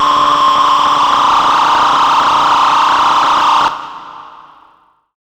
OLDRAVE 6 -R.wav